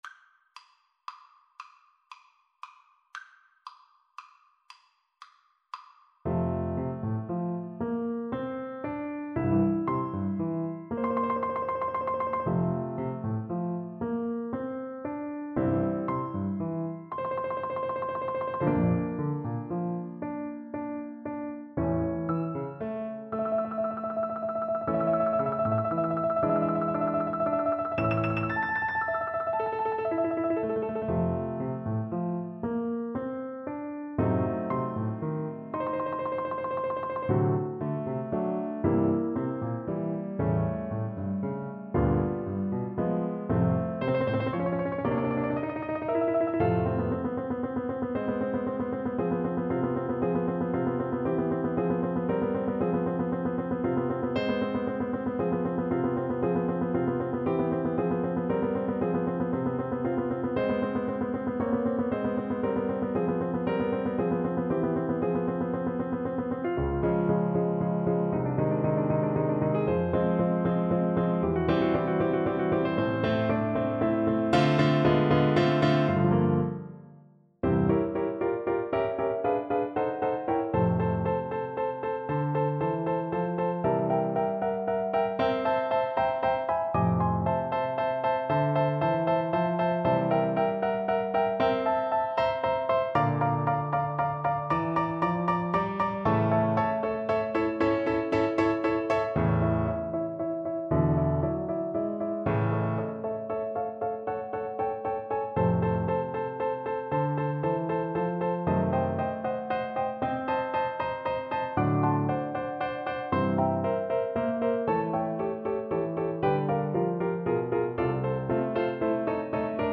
Andantino = 116 (View more music marked Andantino)
Classical (View more Classical Voice Music)